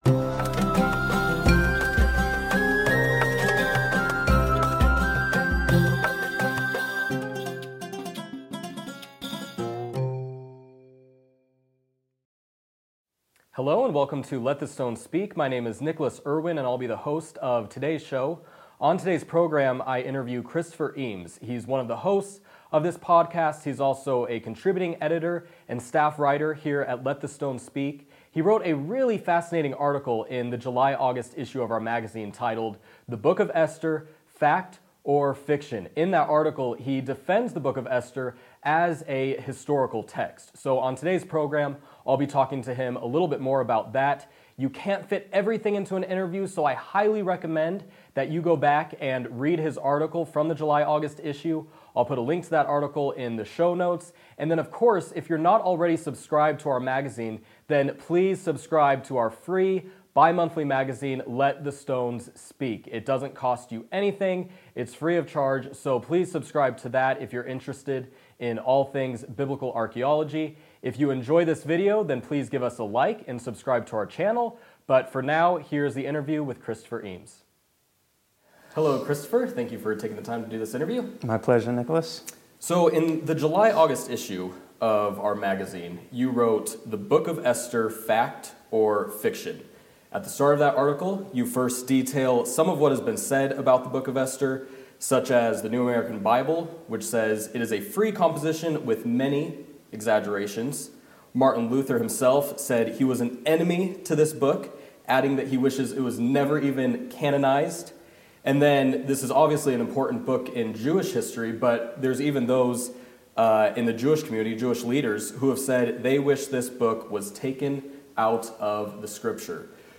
Interview: The Book of Esther—Fact or Fiction?
let-the-stones-speak-44-interview-the-book-of-esther-fact-or-fiction.mp3